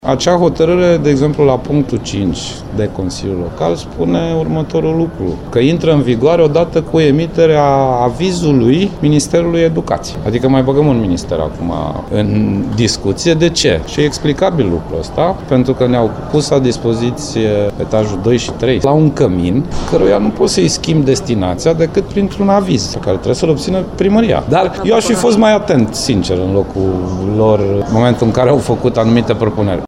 Primăria Timișoara a pus la dispoziția Consiliului Județean Timiș mai multe spații care să fie repartizate structurilor Ministerului Apărării Naționale. Acestea au nevoie însă de avizului Ministerului Educației, spune președintele CJ Timiș, Sorin Grindeanu.